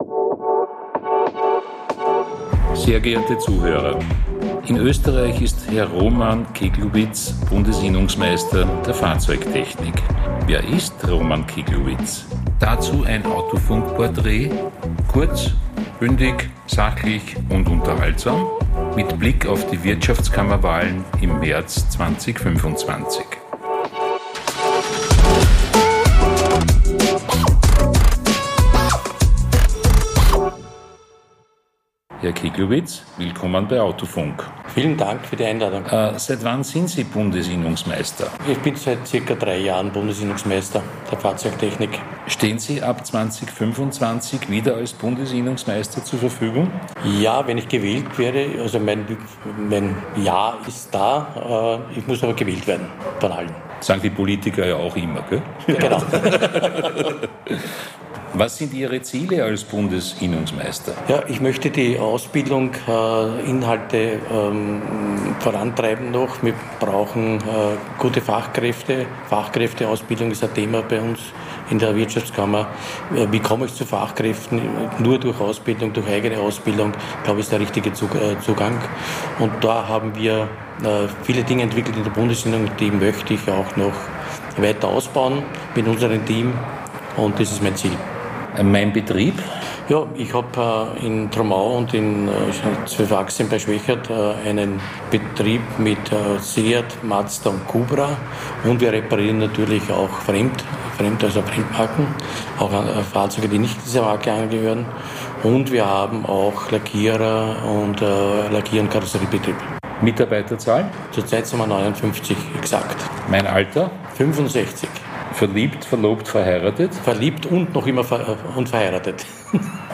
Gespräch.